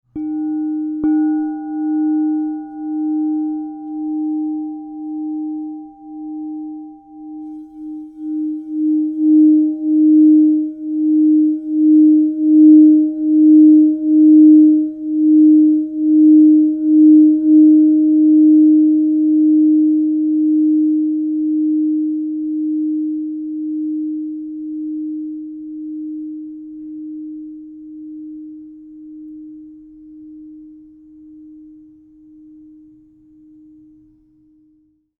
Crystal Tones® White Alchemy Gold 8 Inch D# Singing Bowl
Discover the power of 8″ Crystal Tones® alchemy singing bowl made with White Alchemy Gold, Platinum, Palladium (inside) in the key of D# -20.
432Hz (-)
D#